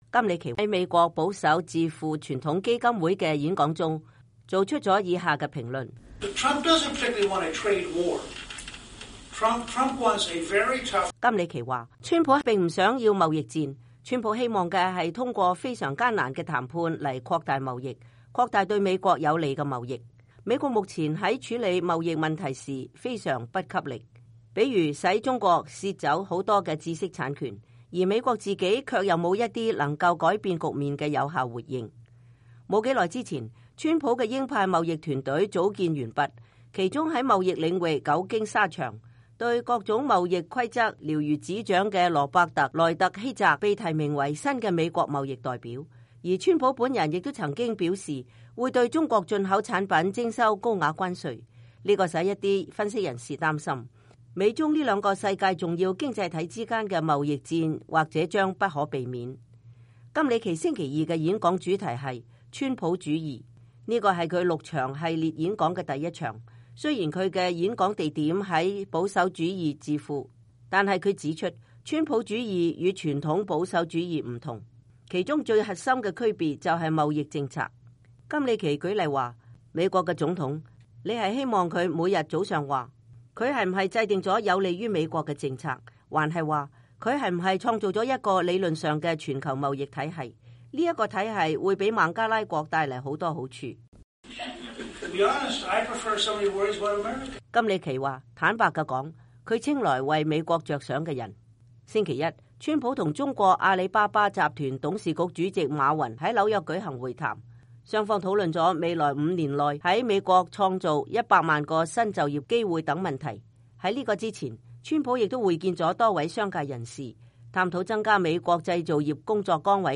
他是在美國保守派智庫傳統基金會的演講中做出的上述評論。